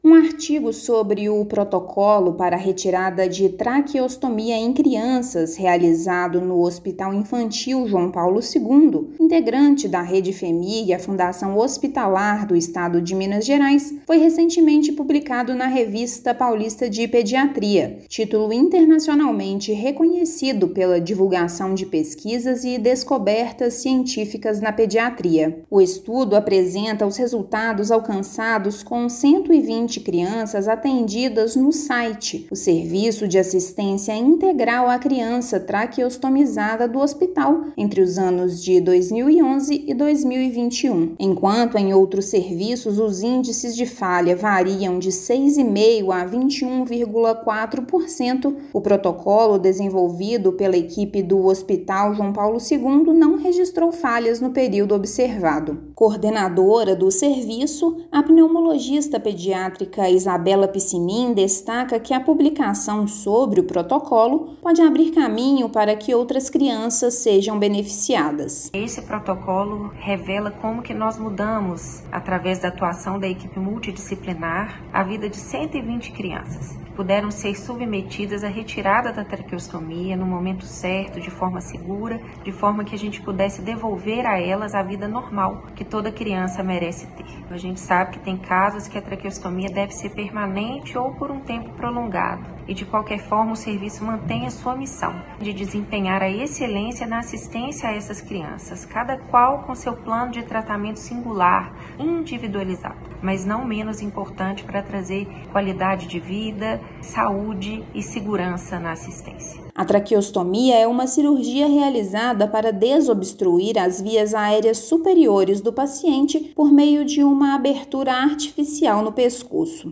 Serviço de Assistência Integral à Criança Traqueostomizada do Hospital Infantil João Paulo II apresenta índices superiores ao de importantes instituições internacionais e é destaque em publicação científica. Ouça matéria de rádio.